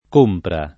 k1mpra] o compera s. f. — solo compra in compra e vendita (oggi com. compravendita) — in ogni altro caso, più com. compera: es. far delle compere; una compera di titoli — solo o quasi compera come term. giur. («prestito pubblico») nella storia genovese: le compere del grano, del sale, i prestiti garantiti dalla gabella del grano, del sale, ecc. — cfr. comprare